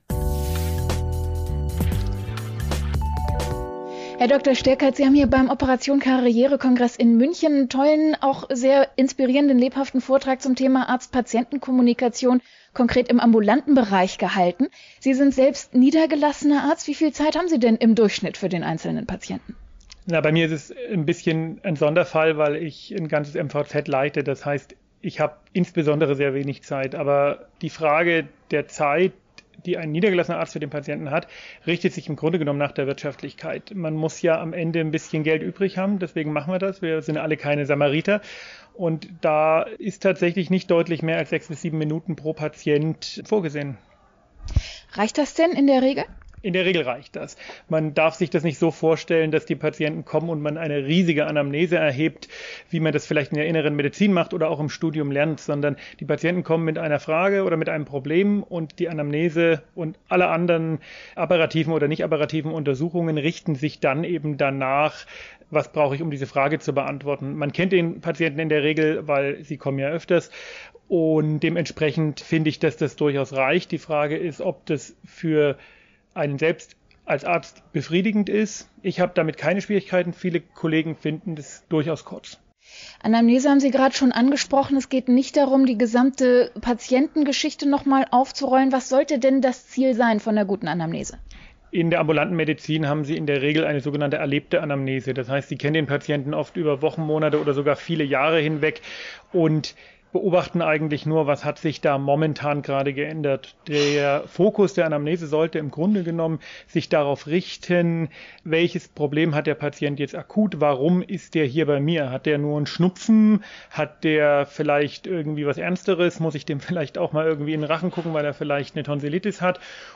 Interview
Das Interview wurde beim Operation Karriere-Event am 9. November 2024 in München aufgezeichnet.